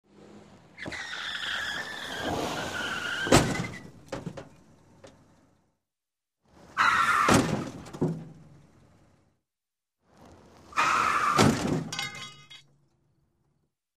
Звуки аварии
Боковой удар (машину занесло)